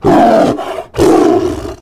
CosmicRageSounds / ogg / general / combat / creatures / tiger / she / prepare1.ogg